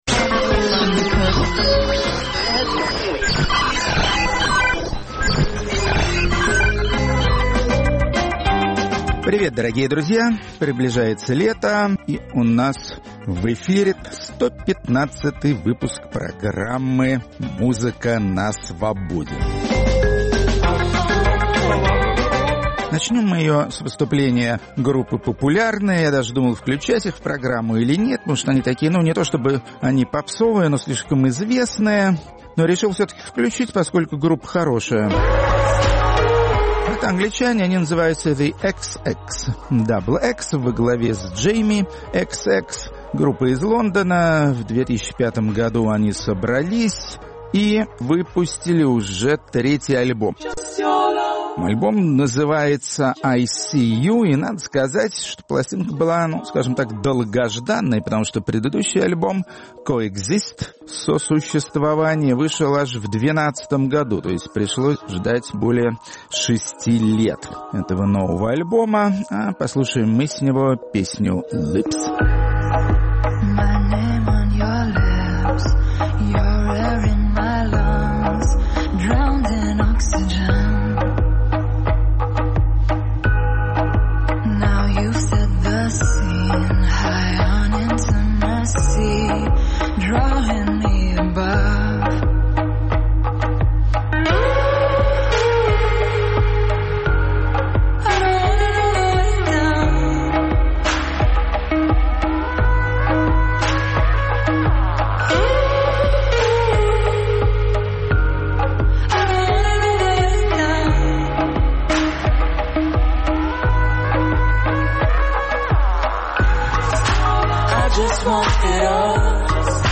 американский гитарист